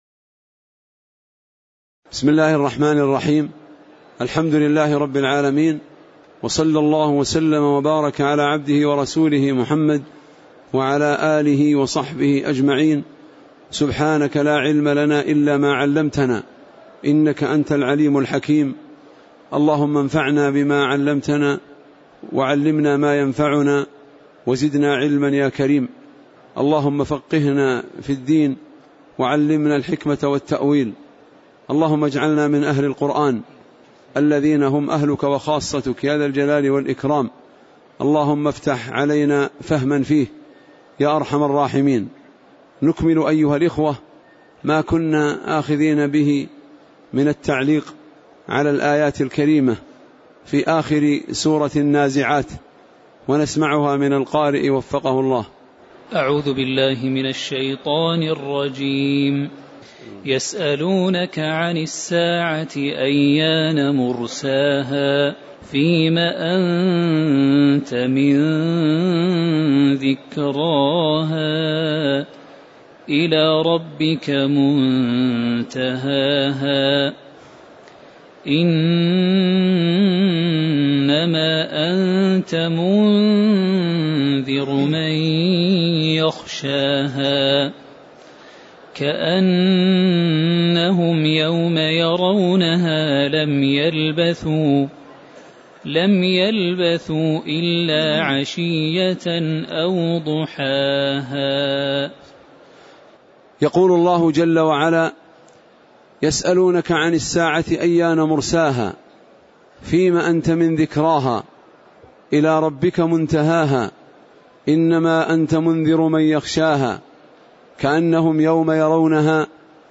تاريخ النشر ١٣ شوال ١٤٣٨ هـ المكان: المسجد النبوي الشيخ